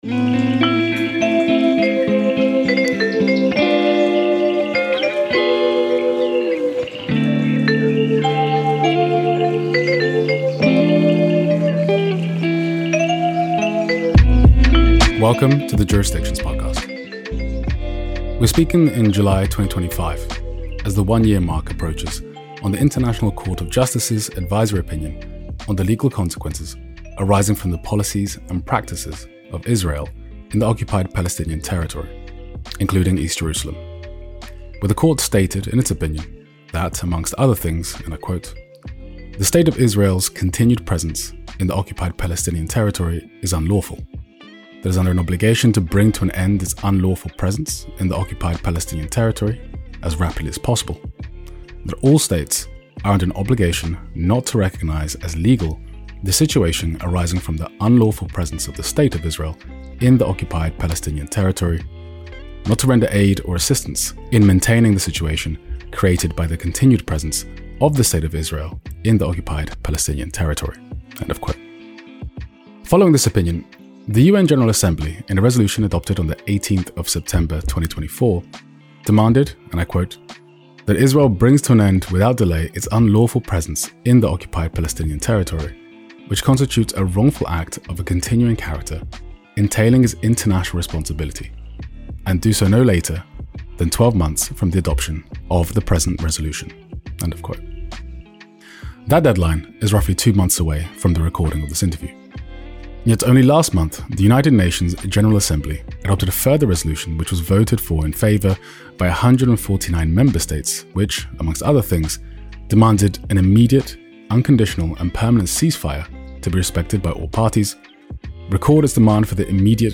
‘The litmus test of Palestine’ - A conversation with Ammar Hijazi, Ambassador and Head of the Palestinian Mission to the Kingdom of the Netherlands